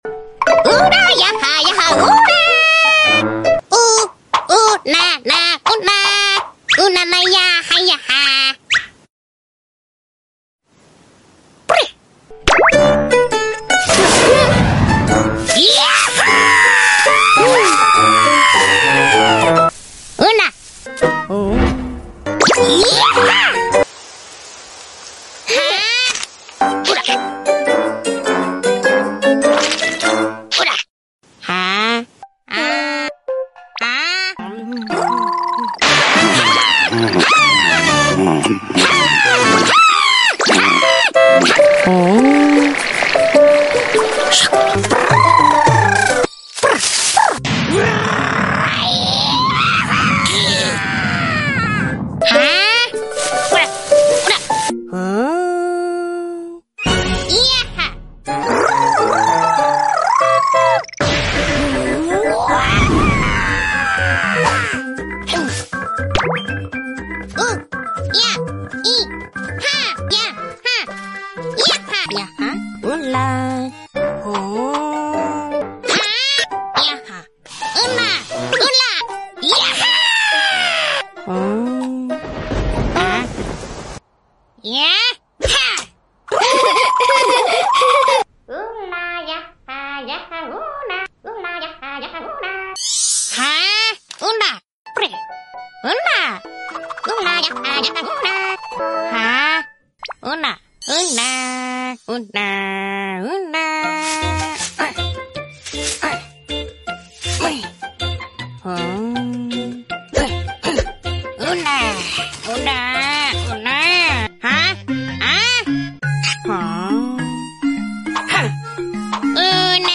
乌萨奇宝宝的声音纯享版来咯！